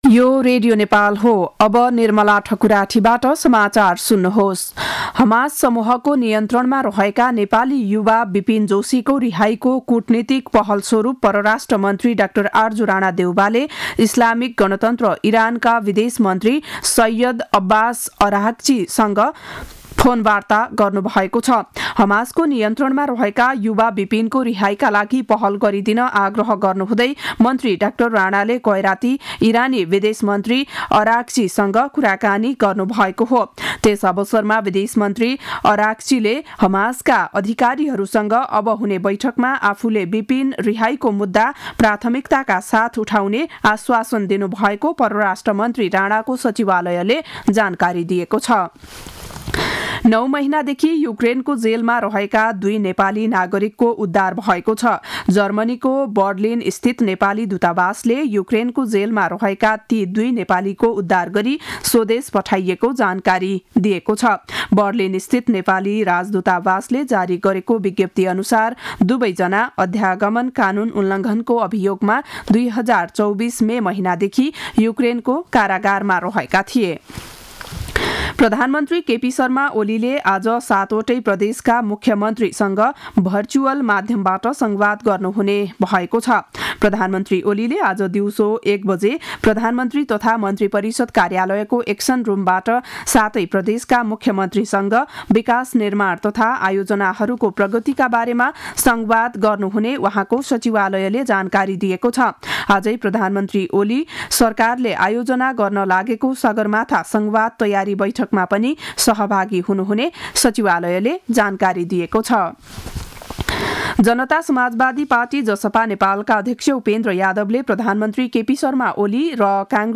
बिहान ११ बजेको नेपाली समाचार : २६ माघ , २०८१
11-am-news-1-2.mp3